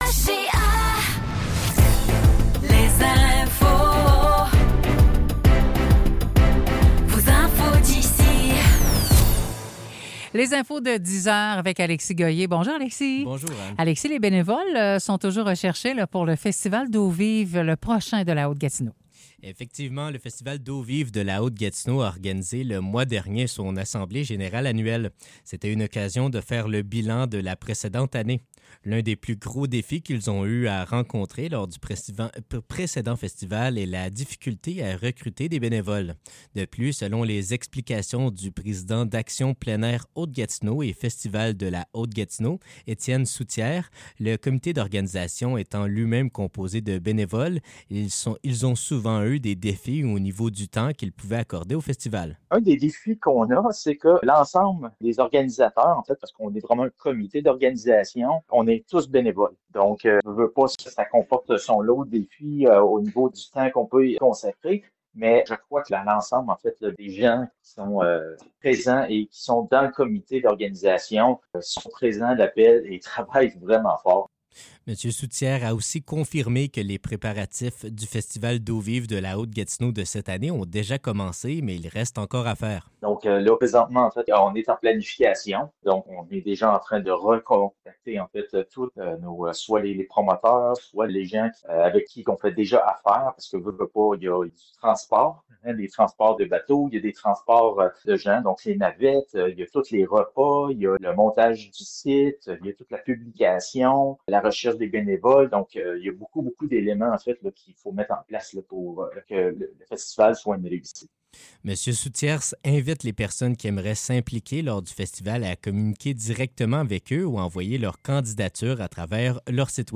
Nouvelles locales - 12 mars 2025 - 10 h